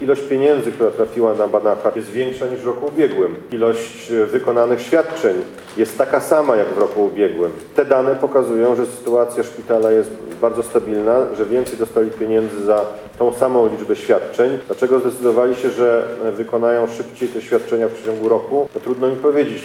Minister zdrowia, Łukasz Szumowski mówi o tym, że szpital na Banacha wyrobił 100 procent swojego ryczałtu.